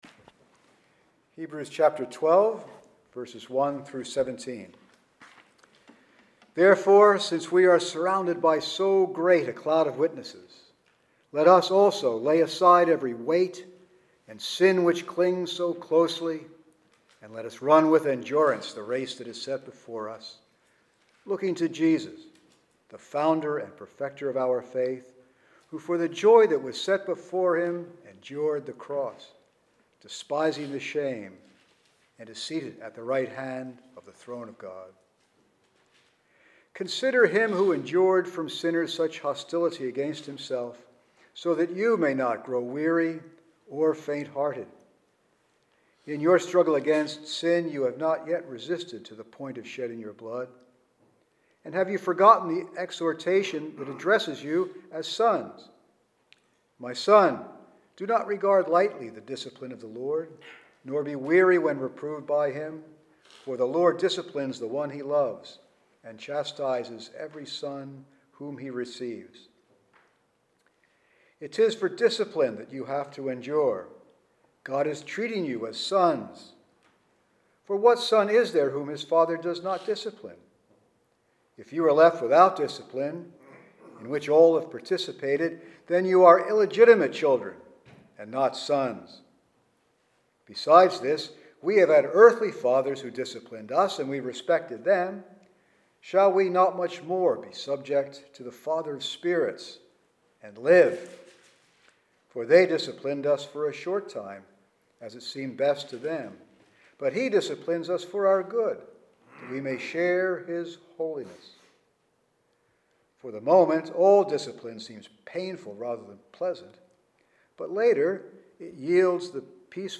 The Cornerstone Church - Sermons